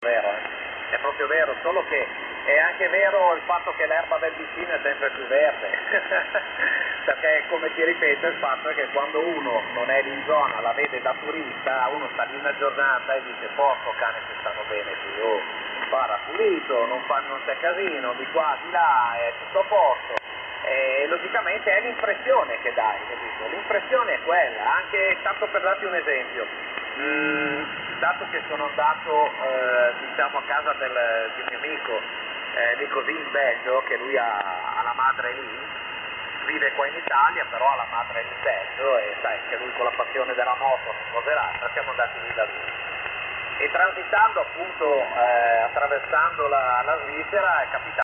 SSB: Single Side Band -  Banda Laterale Unica
SSB su 144 MHz.mp3